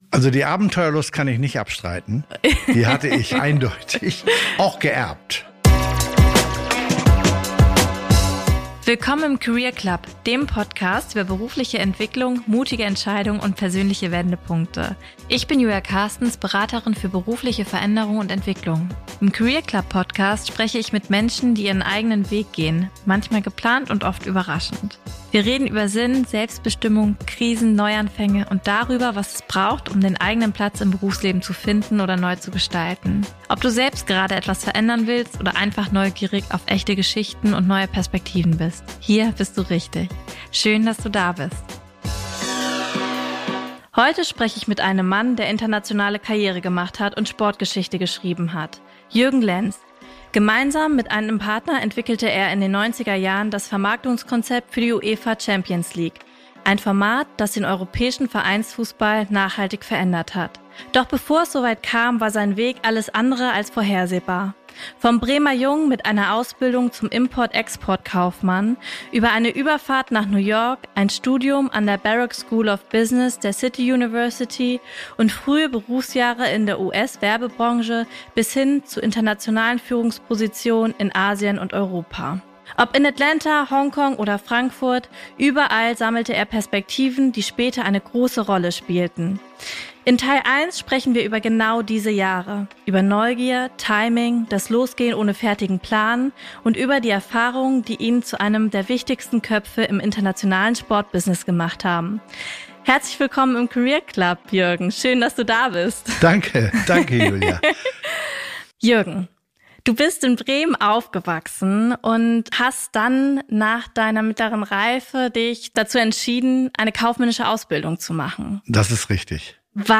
Ein Gespräch über Neugier, Wandel und berufliche Entwicklung und über die Erfahrungen, die ihn zu einem der prägendsten Köpfe im internationalen Sportbusiness machten.